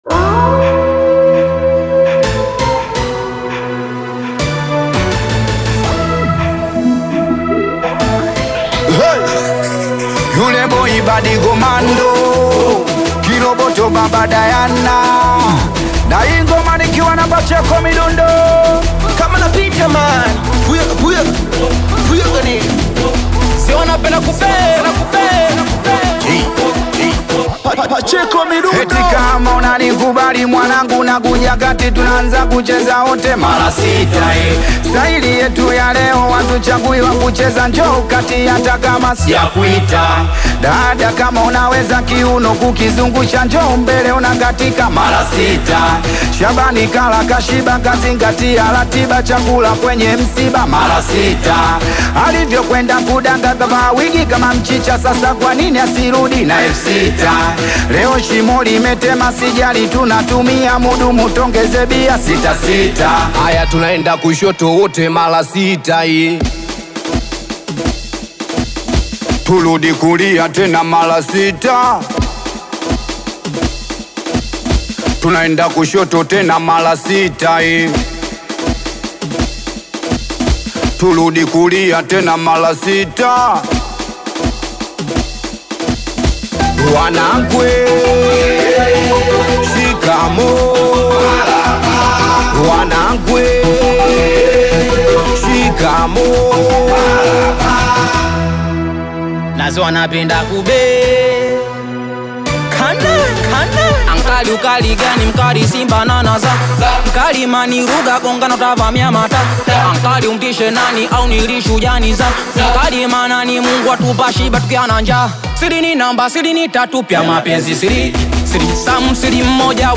AudioSingeli
is a dynamic Afro-beat/Singeli single